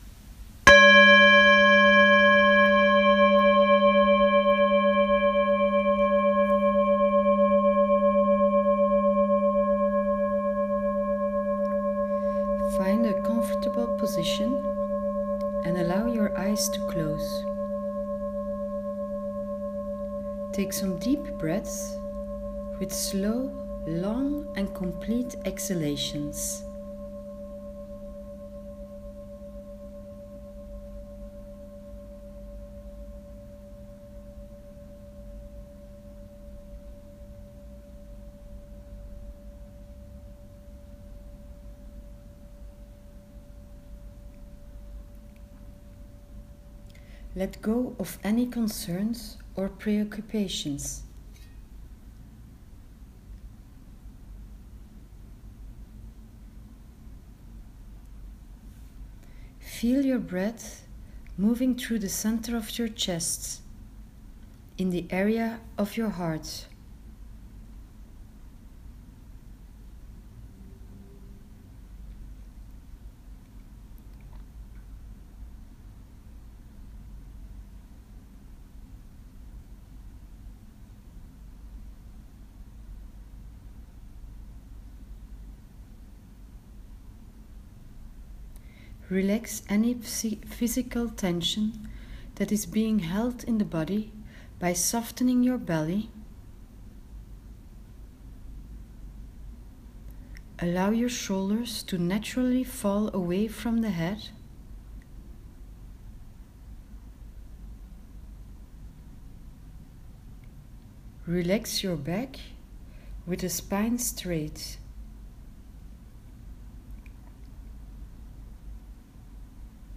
You’ll find the guided meditation as a voice recording under the screen with the introduction video.